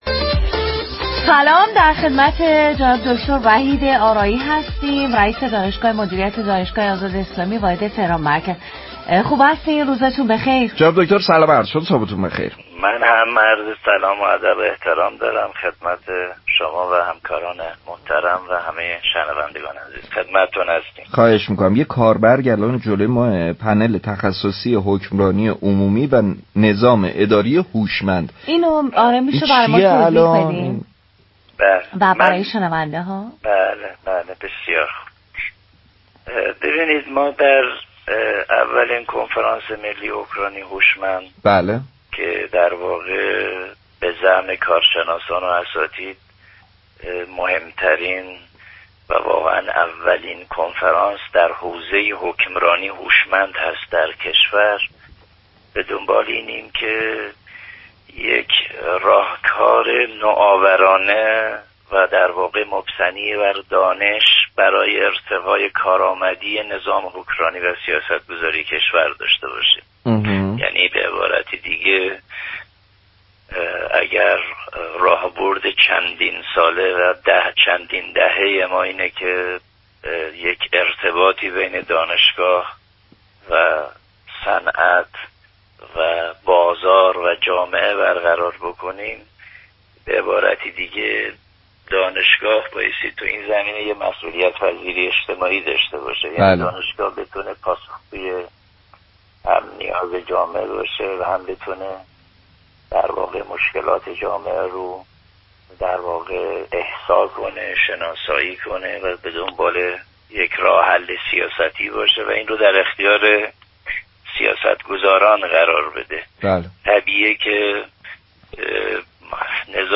در گفتگو با برنامه جونم زندگی رادیو تهران